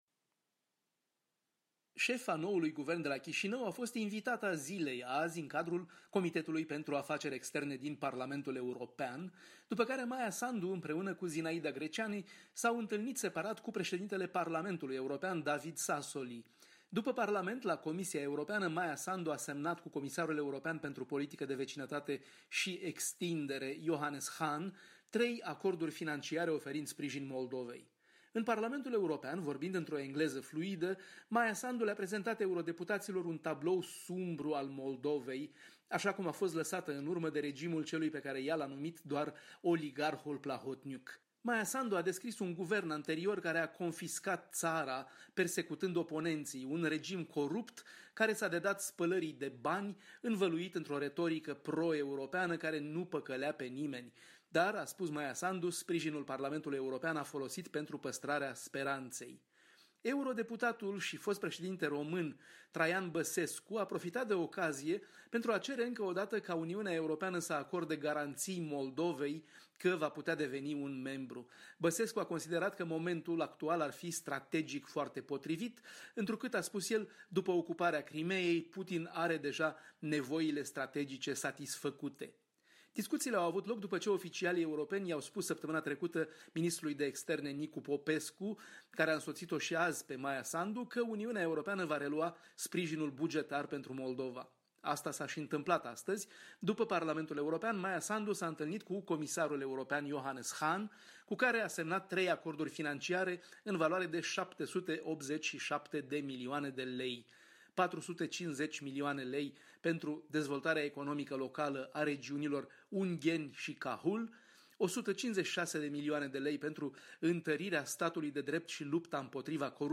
Corespondența zilei de la Bruxelles